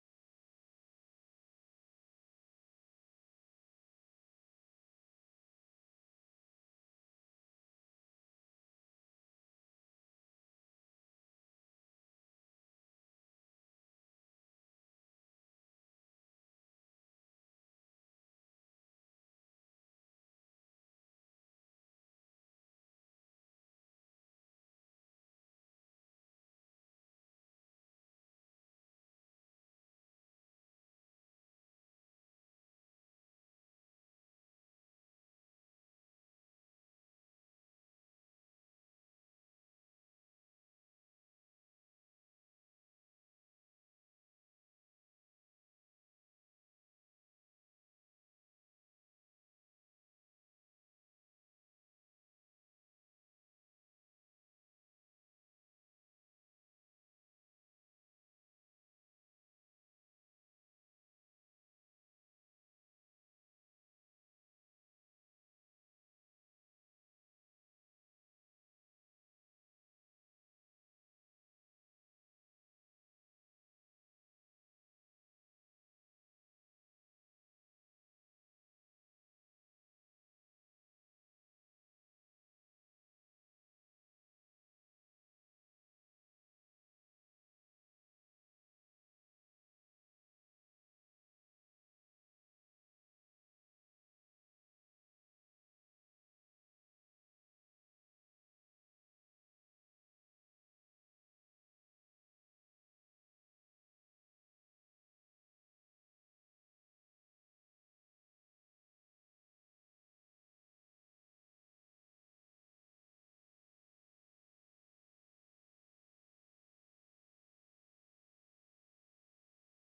Město Varnsdorf: 8. zasedání Zastupitelstva města Varnsdorf Místo konání: Lidová zahrada, Karlova 702, Varnsdorf Doba konání: 26. října 2023 od 16:00 hod. 1.